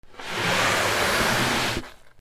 布ずれ（引きずる）
/ J｜フォーリー(布ずれ・動作) / J-05 ｜布ずれ